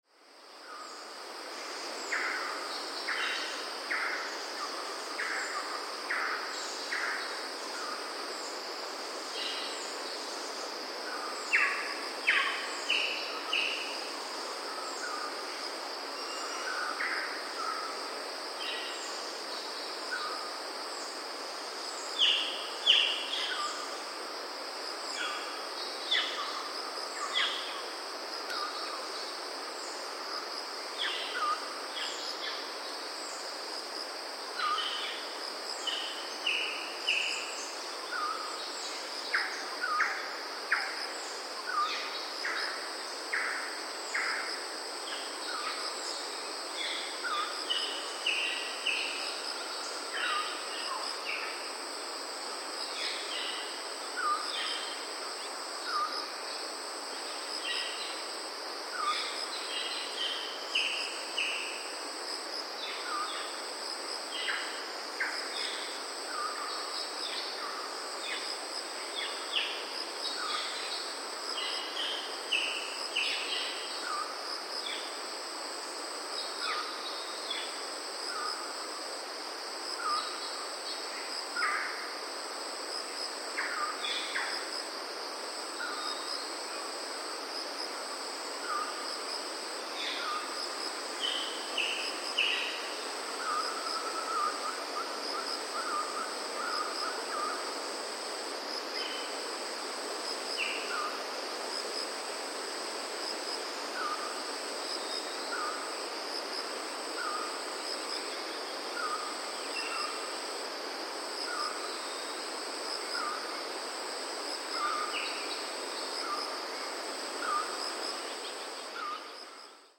Rainforest
It is very rich in the sounds of birds confined to rainforest as well as others distributed throughout wet-zone evergreen forests, and of mammals and insects.
Morning Soundscapes
(Rode NT4 XY stereo on Marantz PMD671)
(Note: wind sound in background is heavy due to monsoon winds in June)
Sinharaja-2_Rode-XY.mp3